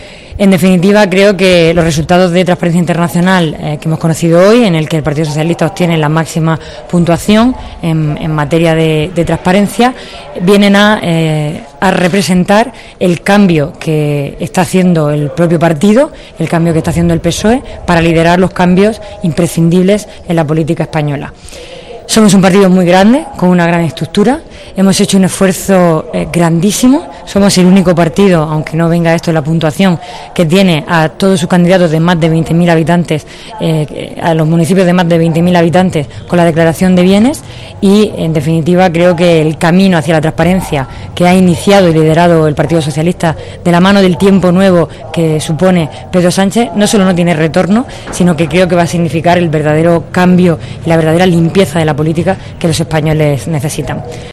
Declaraciones de María González Veracruz sobre el 10 que ha concedido Transparencia Internacional al PSOE